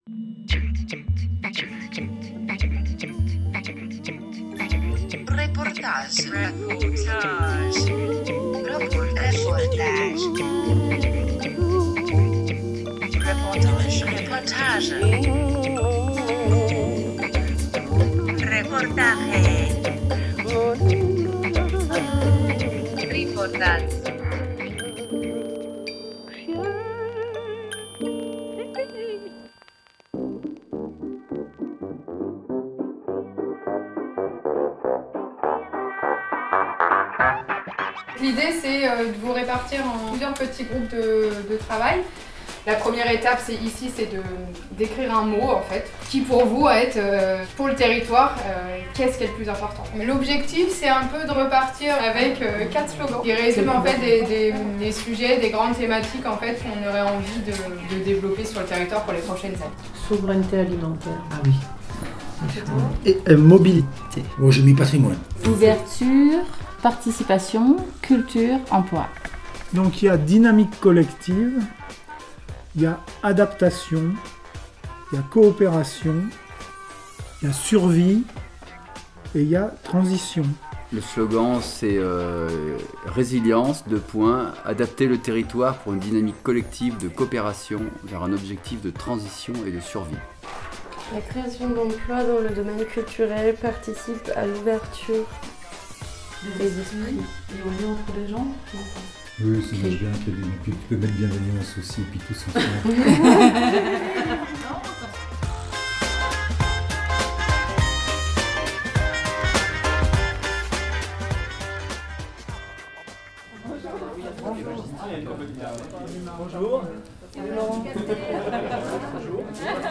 Le vendredi 2 juillet se tenait la journée de restitution du programme LEADER à la maison de l’Agriculture de Cléon d’Andran.
reportage